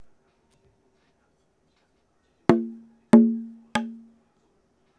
マイクロフォンは、SONYのワンポイントステレオ録音用、ECM-MS907を使いました。
6 これは、パーランクーの、（１）中心、（２）中心と淵の中間、（３）淵の木、の順に軽く打ってみたものです。